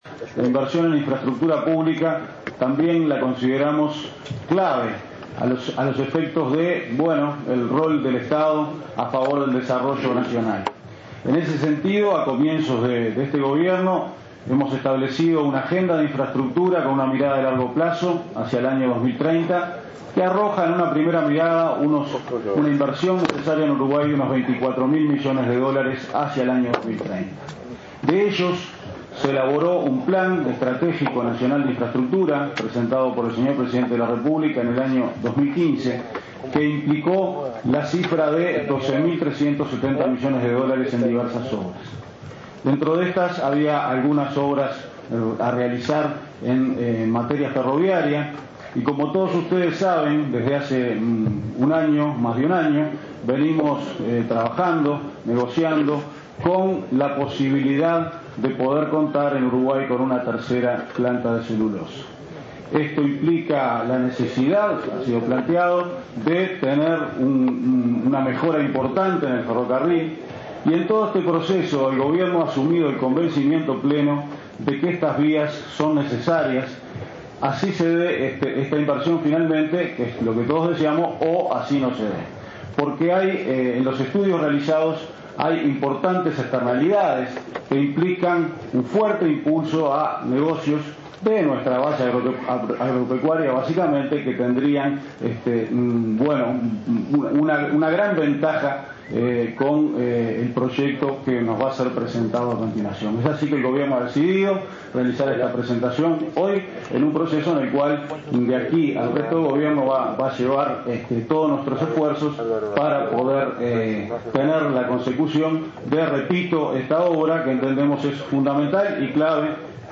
El director de la OPP, Álvaro García, destacó este martes durante la presentación del proyecto Ferrocarril Central que la inversión en infraestructura pública es considerada clave por el Gobierno debido al rol del Estado a favor del desarrollo nacional. Recordó que el Plan Nacional de Infraestructura implicó una inversión de 12.500 millones de dólares y que esta obra será un fuerte impulso para diversos negocios.